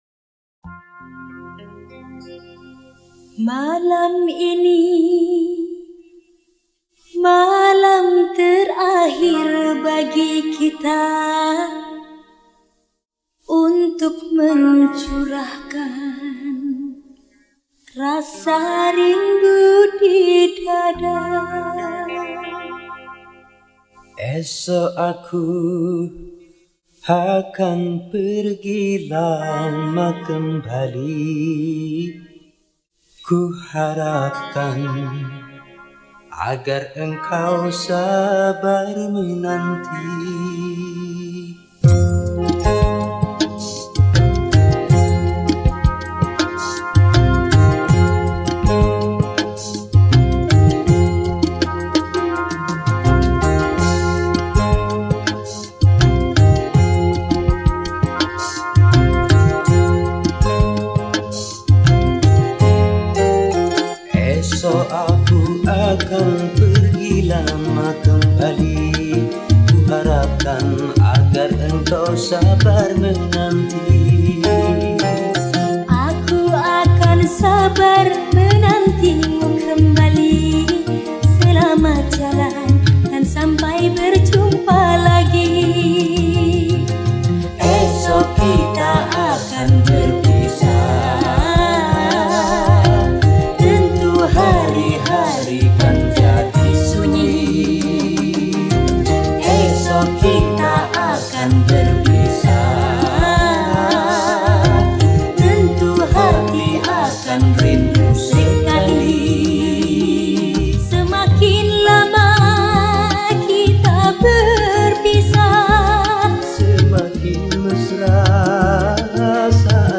Genre Musik                        : Dangdut